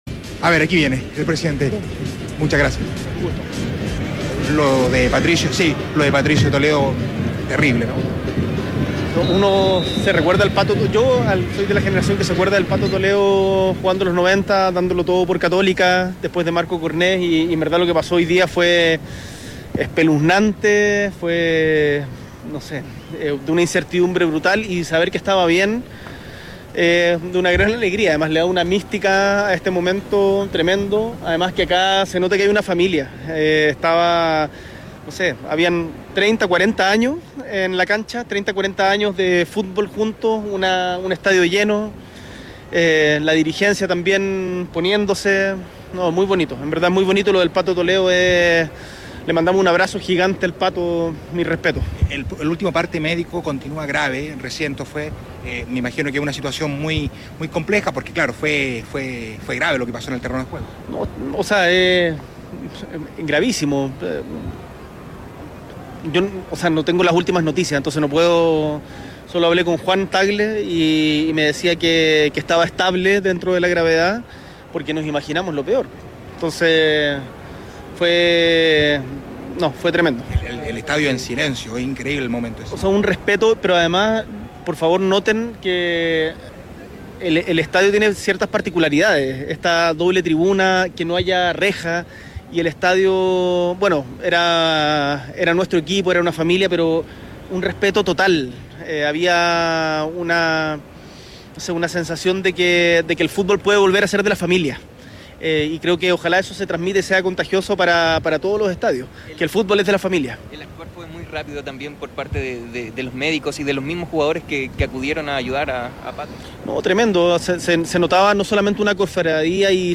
“Le mandamos un abrazo gigante”, aseveró el Mandamás en conversación con ADN Deportes.
Presente en el Claro Arena estaba el Presidente de la República, Gabriel Boric.